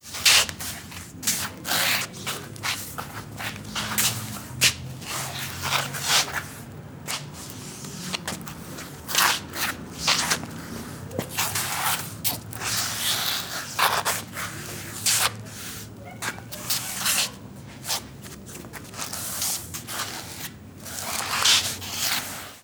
Arrastrar los pies al bailar un tango
Sonidos: Gente
Sonidos: Acciones humanas